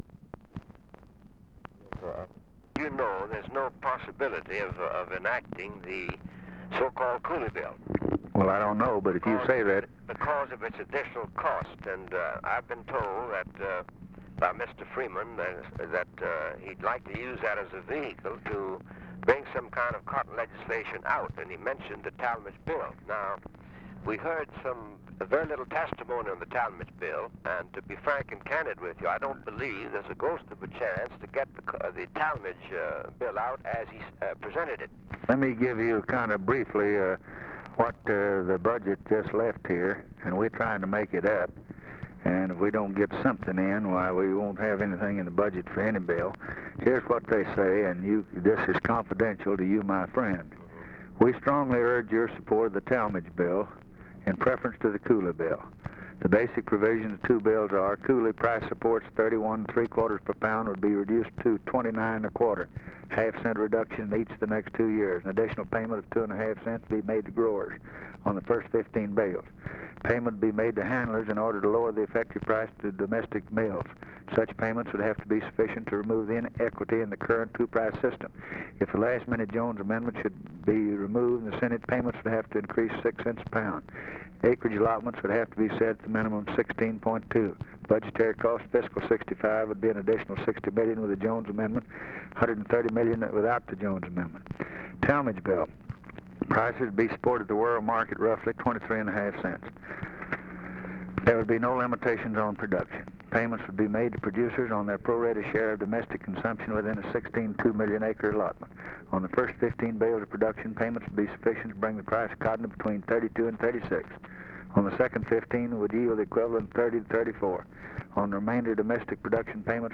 Conversation with ALLEN ELLENDER, December 10, 1963
Secret White House Tapes